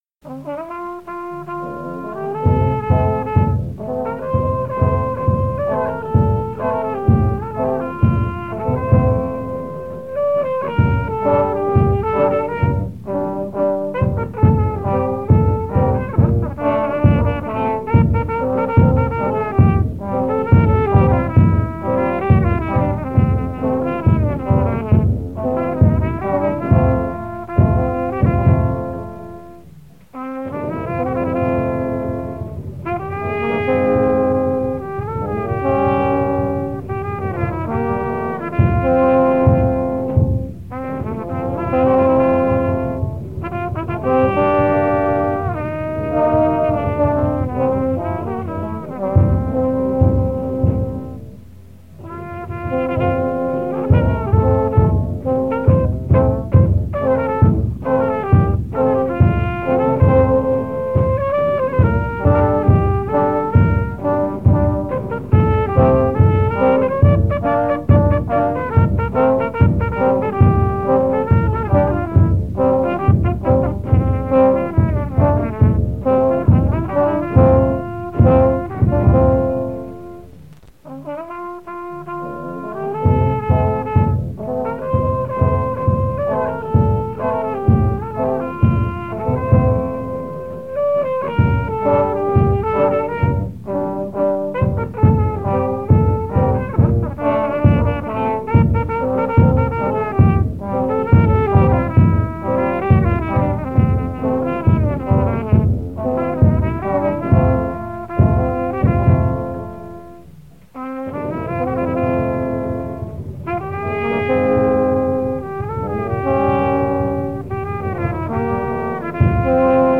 Keywords: música de navidad
Grabaciones de campo
Música de banda para el nacimiento del Niño Dios (Guerrero); 10.